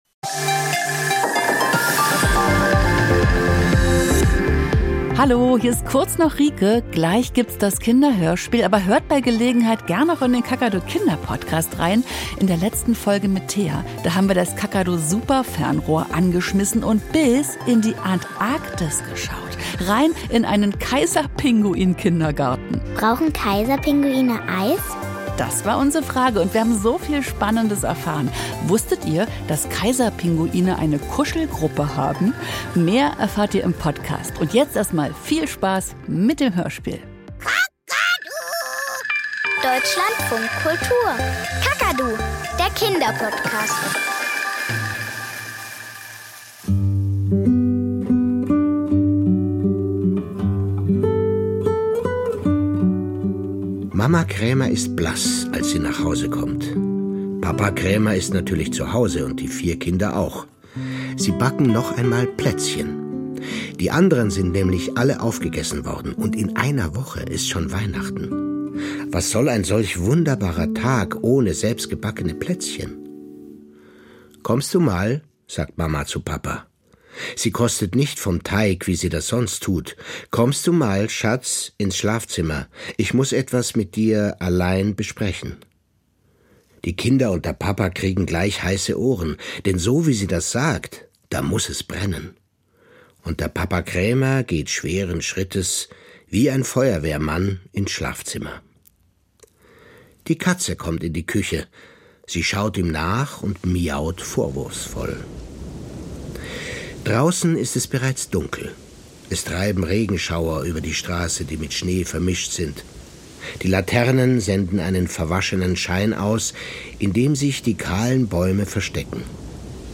Bitte keine Lernspiele: Ein Kinder- und Jugendpsychiater erklärt, wie Eltern gute Weihnachtsgeschenke für ihre Kinder finden. Und warum es nicht schlimm ist, wenn sie sich nicht bergeweise Geschenke leisten können.
Das Interview im Deutschlandfunk Kultur greift kulturelle und politische Trends ebenso auf wie...